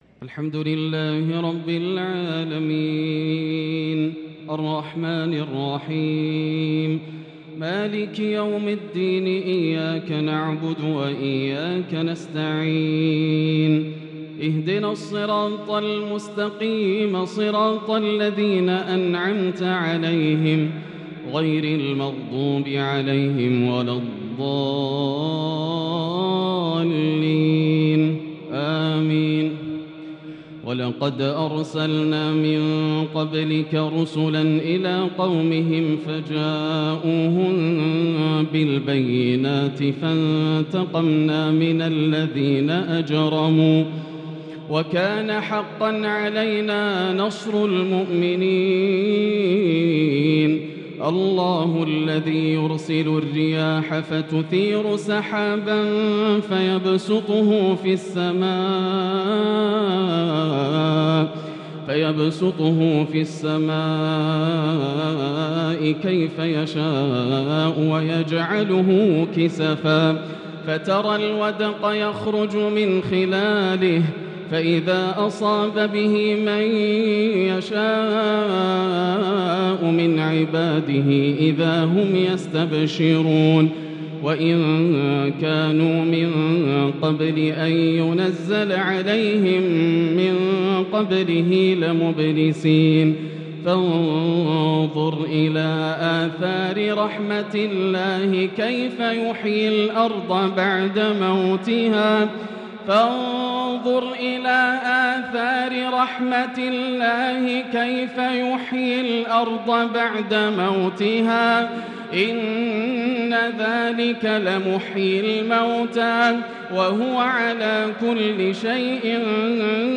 صلاة التراويح ليلة 24 رمضان 1443هـ l سورة الروم 47 _ سورة لقمان كاملة | taraweeh prayer The 24th night of Ramadan 1443H | from surah Ar-Rum and Luqman > تراويح الحرم المكي عام 1443 🕋 > التراويح - تلاوات الحرمين